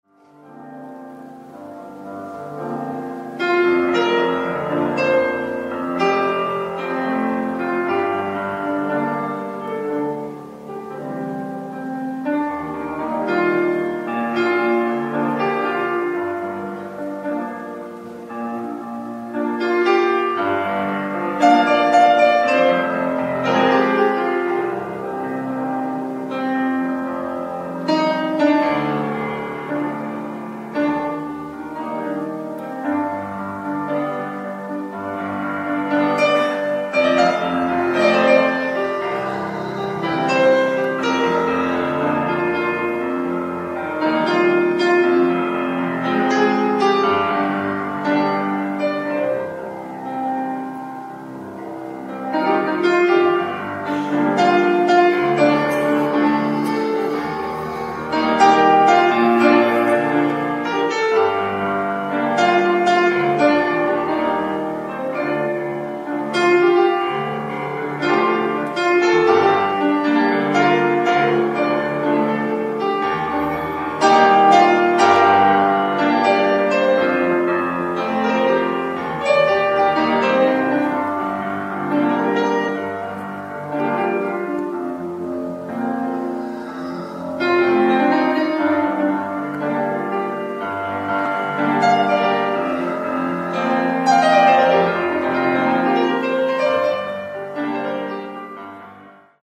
Live At Sejong Center, Seoul, South Korea October 6th, 2010